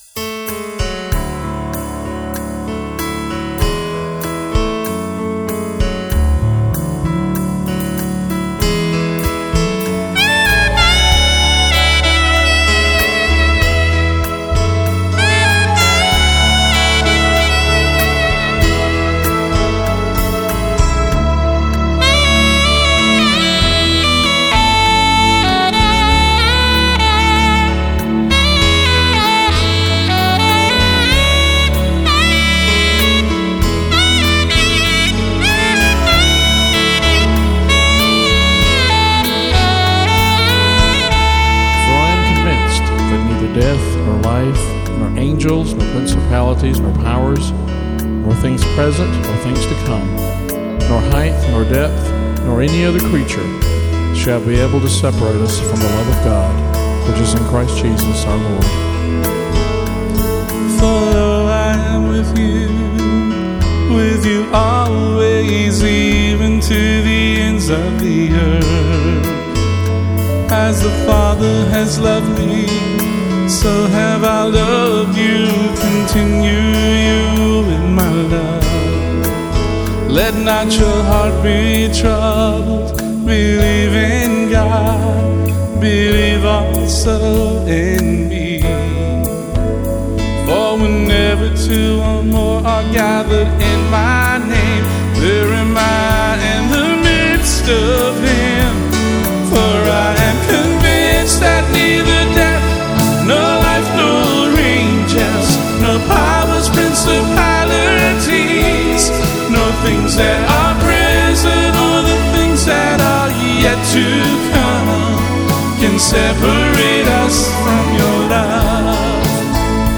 Alto Sax
Guitar